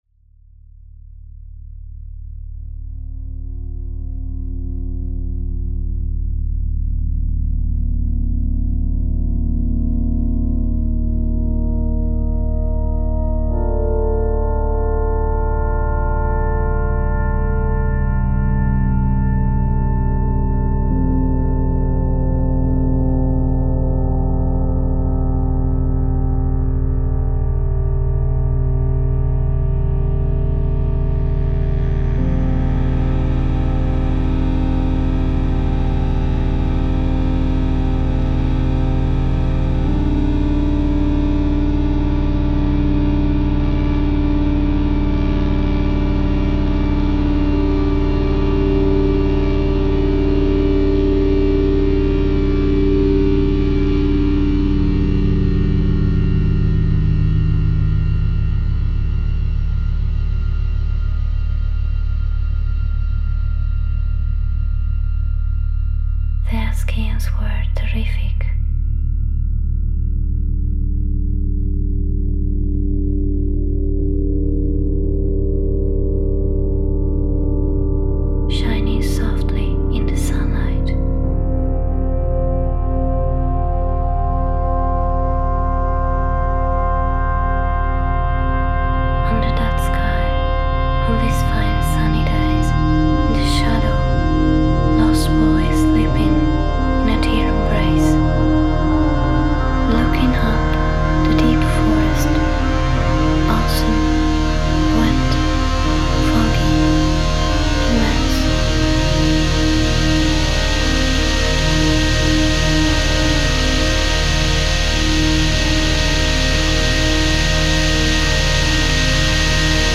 Mumbling as a bass voice.
SOUND WORK